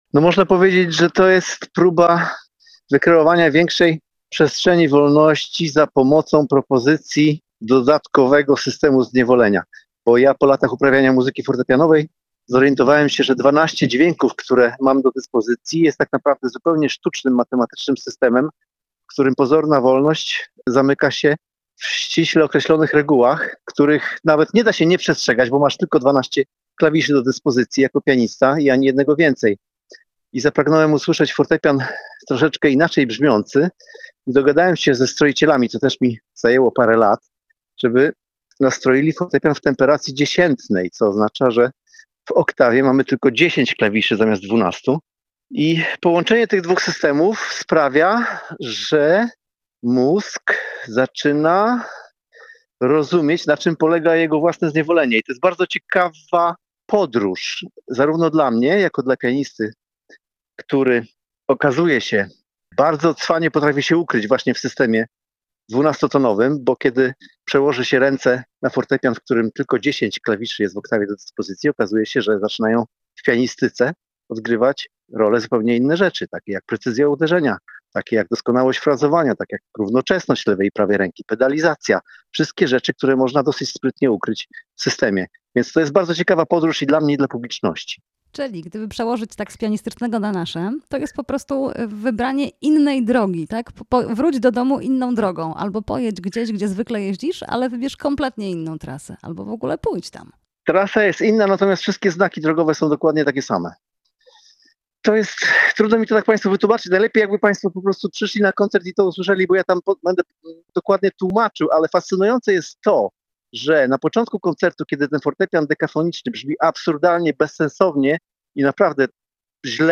Jazz a Vu: Leszek Możdżer i koncert na dwa fortepiany [POSŁUCHAJ ROZMOWY]